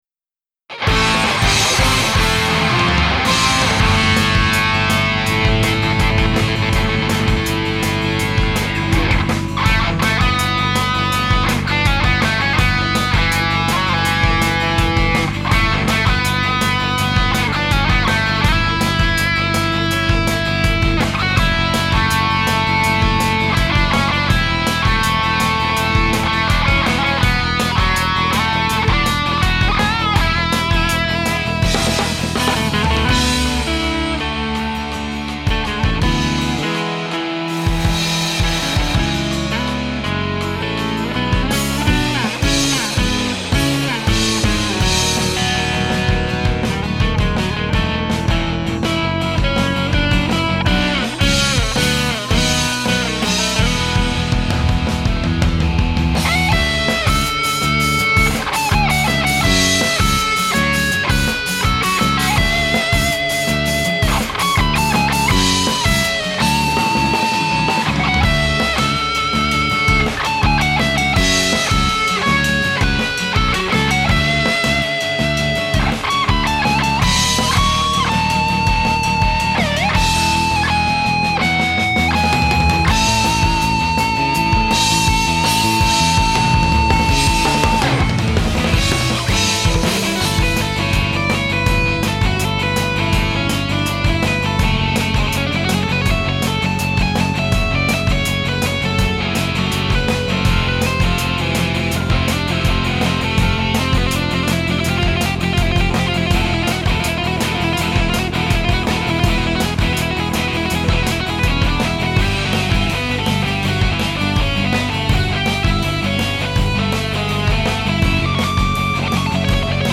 ソーティ区画A~Dで流れるコンテンツBGMのアレンジです。